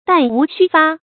弹无虚发 dàn wú xū fā 成语解释 虚：空。子弹没有一颗不命中目标。形容射得很准。
成语繁体 彈無虚發 成语简拼 dwxf 成语注音 ㄉㄢˋ ㄨˊ ㄒㄩ ㄈㄚ 常用程度 常用成语 感情色彩 中性成语 成语用法 主谓式；作谓语、宾语；含褒义，指百发百中 成语结构 主谓式成语 产生年代 近代成语 成语正音 弹，不能读作“弹钢琴”的“tán”。